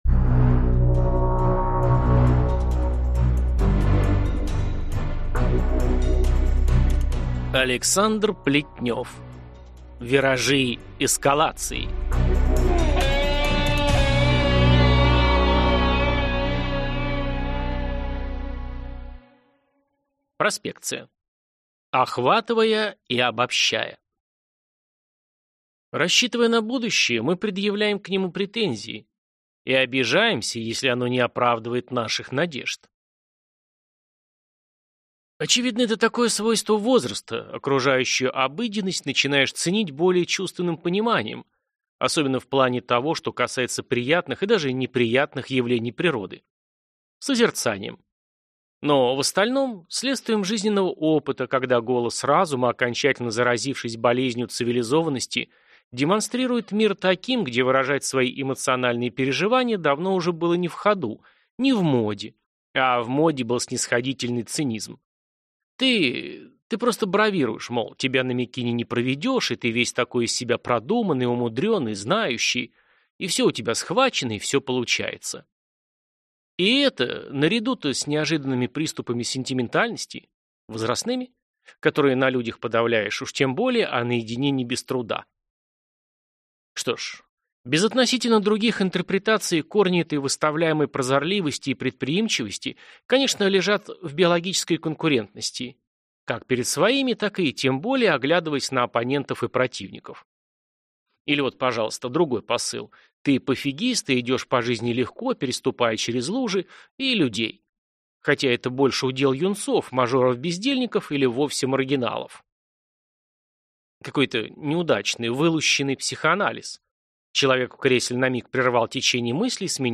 Аудиокнига Виражи эскалации | Библиотека аудиокниг